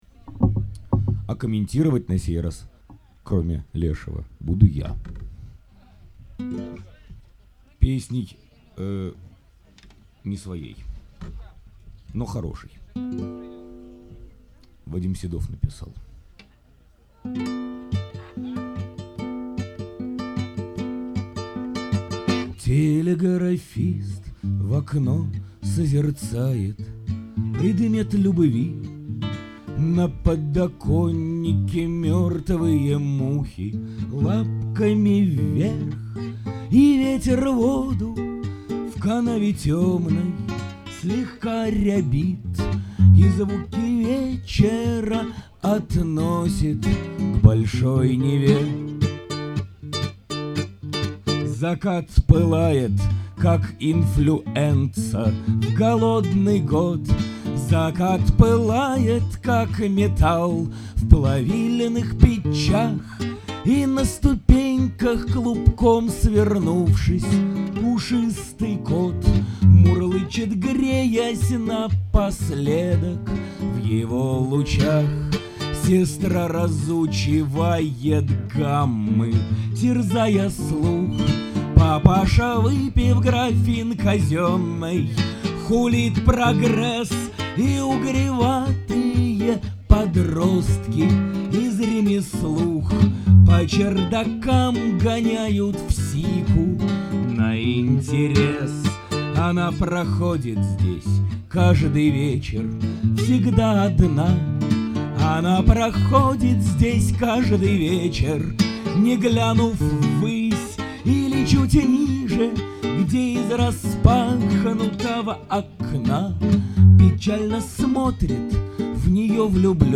Запись концерта БС2002